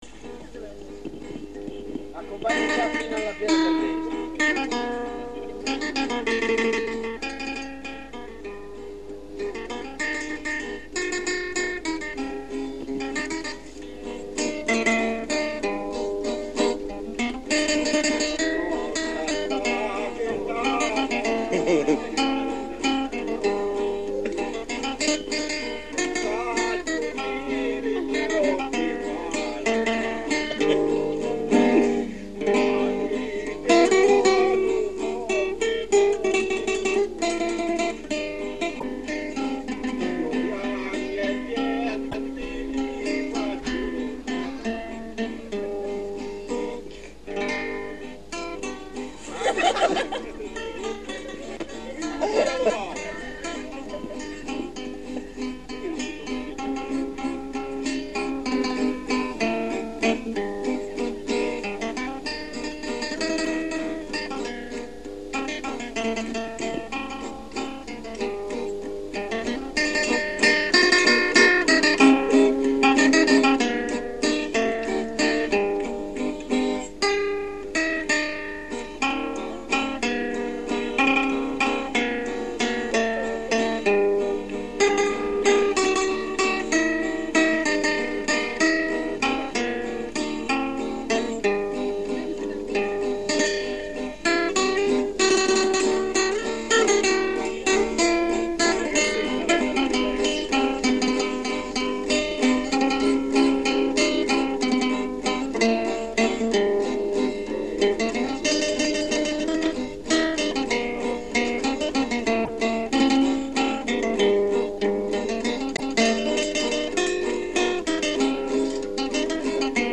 I documenti presenti in questo archivio sono in formato mp3 e sono stati digitalizzati e restaurati recentemente da supporti audio tradizionali. La qualità di alcuni, comunque, non è ottimale.